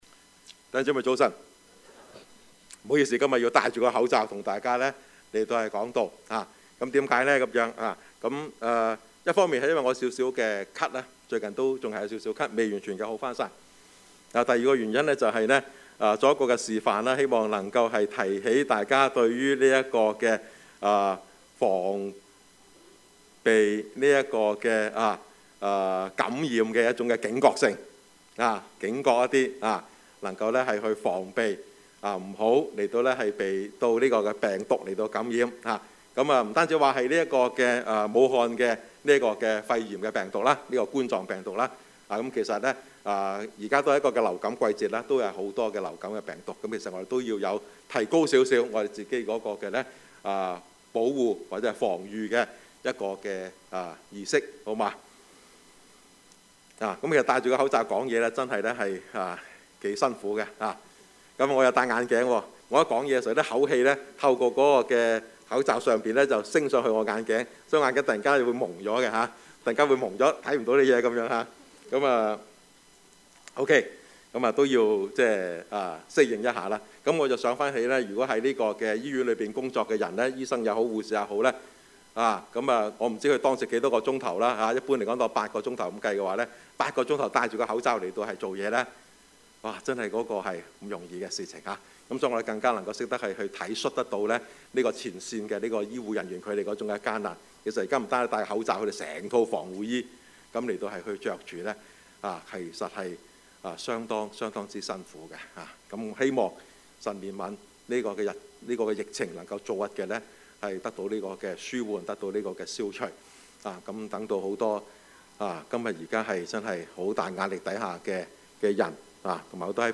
Service Type: 主日崇拜
Topics: 主日證道 « 大復興 埋葬 »